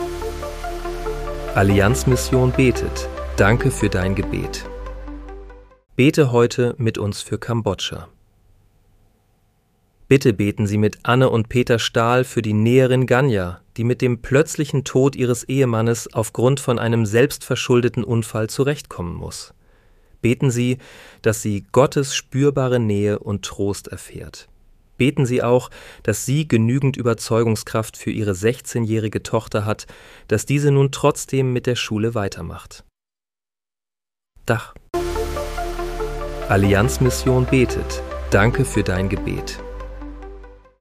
Unsere Gebets-News als täglicher Podcast
Bete am 16. April 2026 mit uns für Kambodscha. (KI-generiert mit